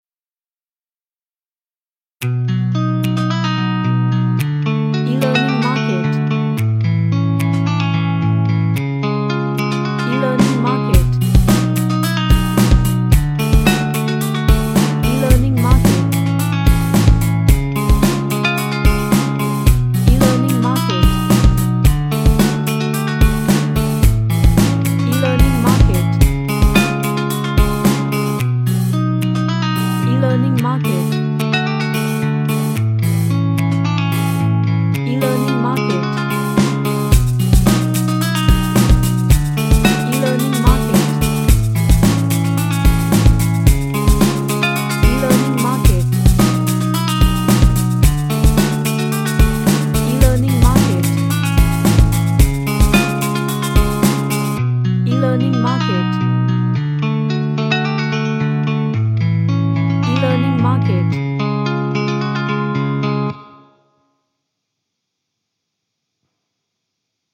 A Happy Playful Grovy Rock Track.
Groovy